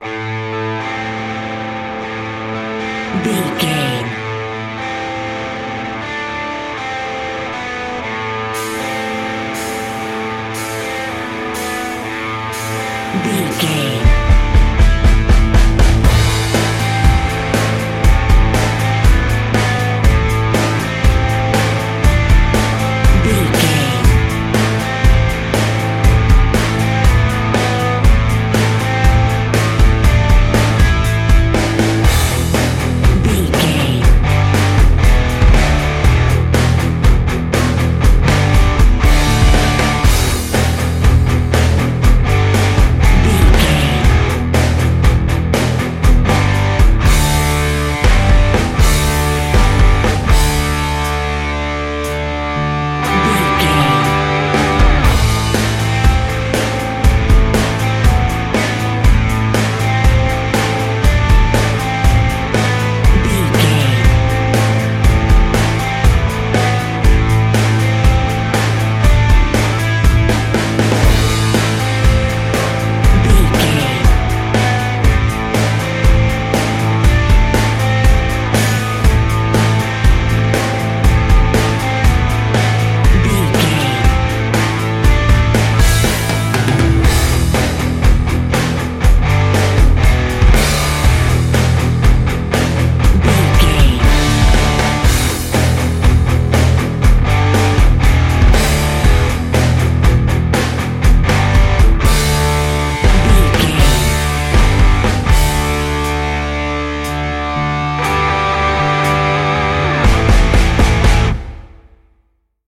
Aeolian/Minor
drums
electric guitar
bass guitar
Sports Rock
hard rock
angry
lead guitar
aggressive
energetic
intense
powerful
nu metal
alternative metal